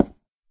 wood1.ogg